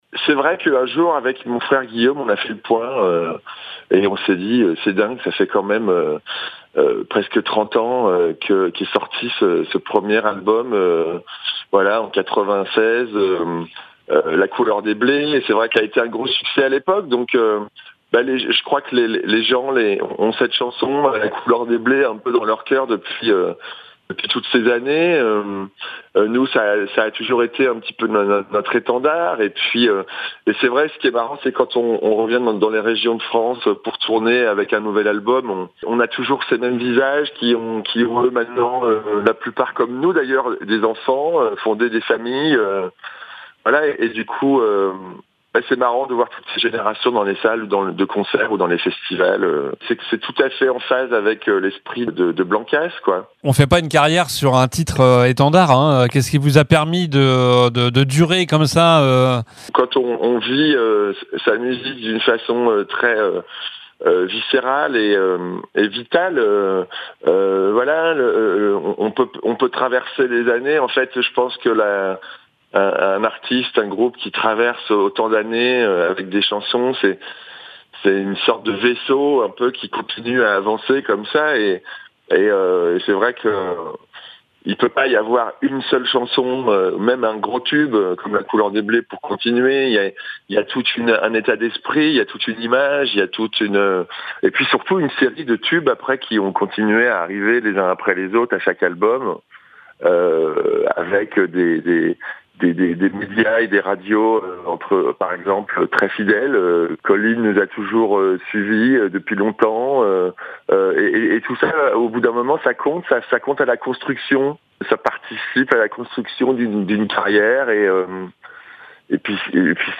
Entretien avec Blankass en concert à Niort ce vendredi